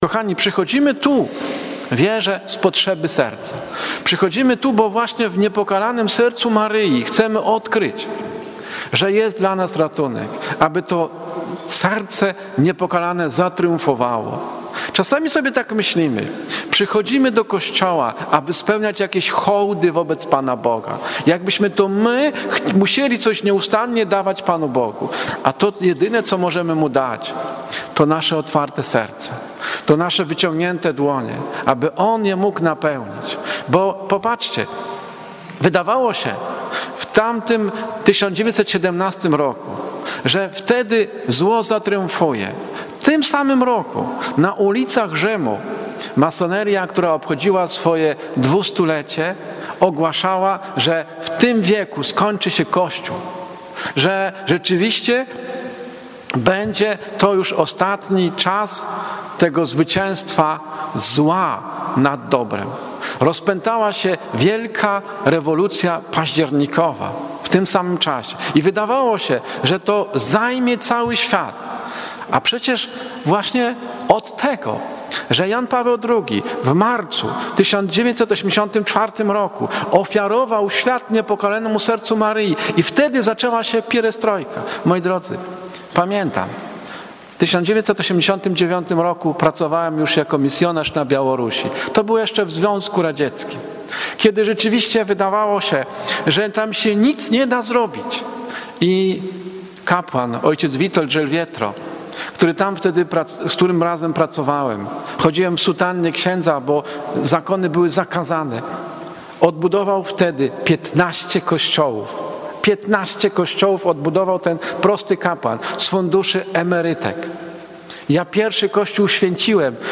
Kazanie Maryjne z soboty 03.12.2016 godz. 19.30
Kazanie-Maryjne.mp3